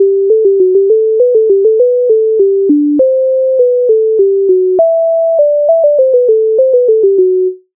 MIDI файл завантажено в тональності G-dur